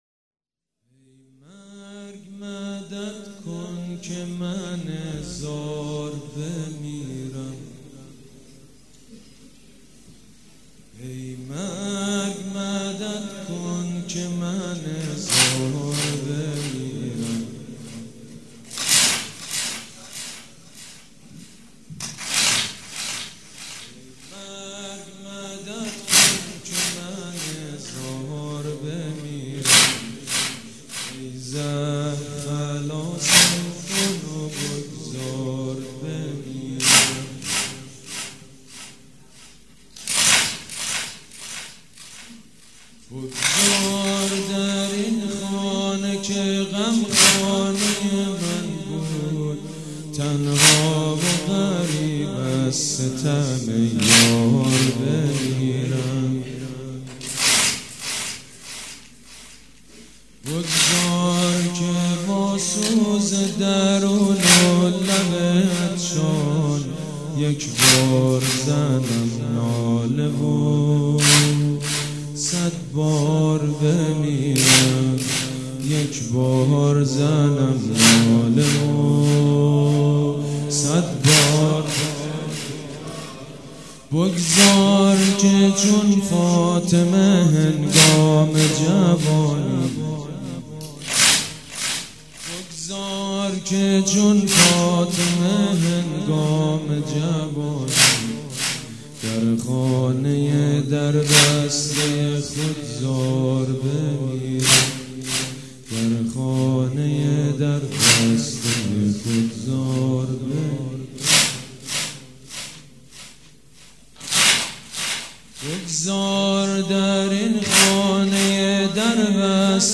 «شهادت امام جواد 1394» واحد: ای مرگ مدد کن که من زار بمیرم